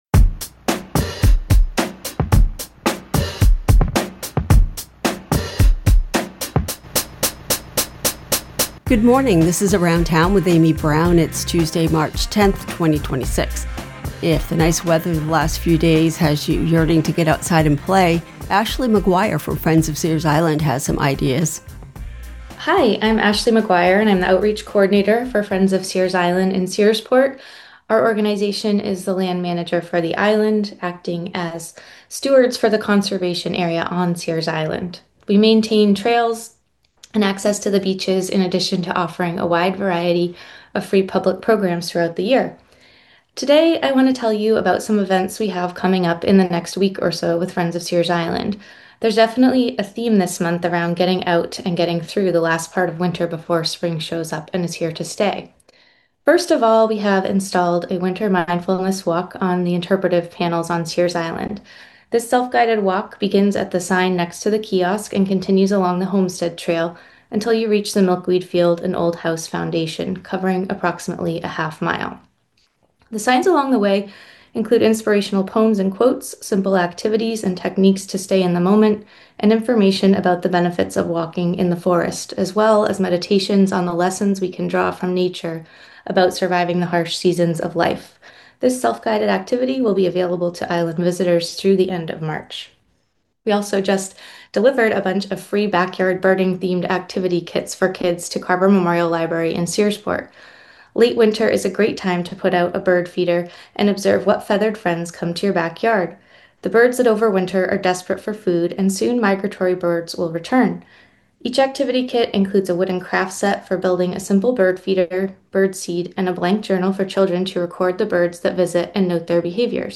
Maine AFL-CIO rally at the Statehouse for affordable childcare Part 1 of 2 (Part 2 of 2. Part 1 aired Friday, 3/6/26)